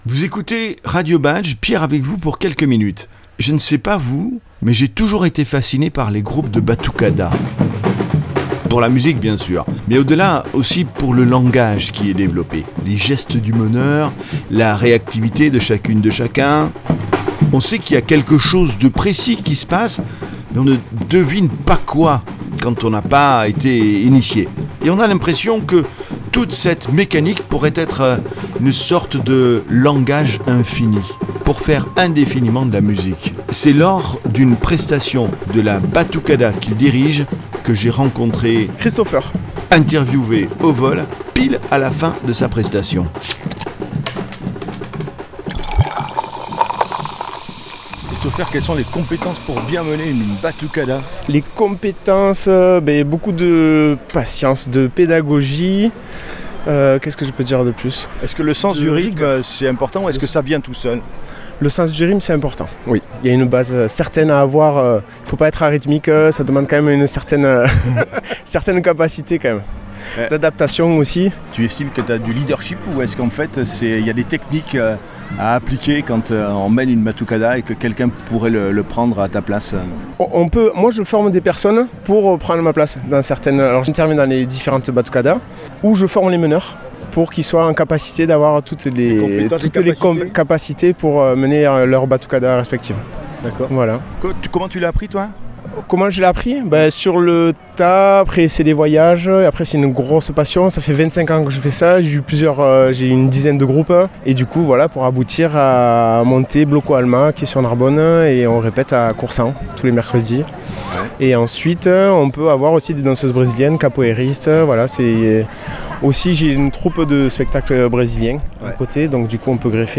Une Batucada joue et agit comme un seul et même corps de percussions, de sifflets, de pulsations. A sa tête, le meneur est un chef d'orchestre au langage d'initié.